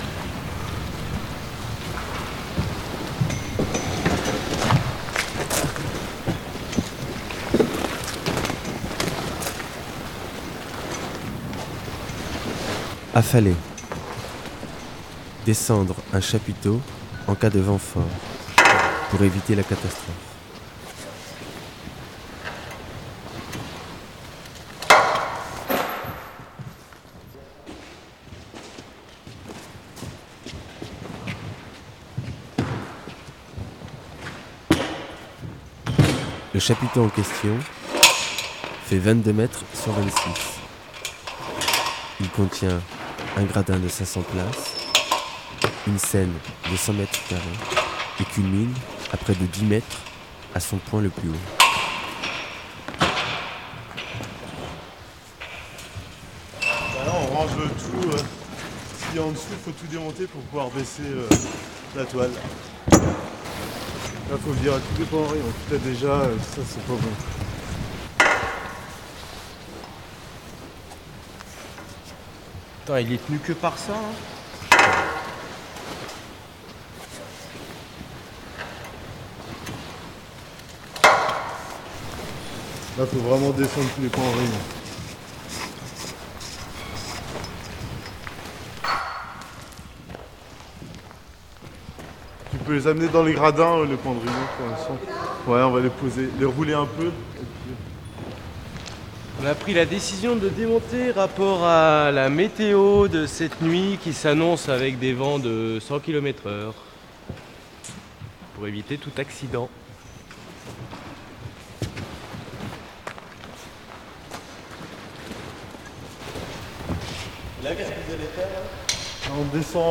Pastille sonore de 10 minutes, pour vivre l'affalage d'un chapiteau, celui de la Cie Branle-Bas.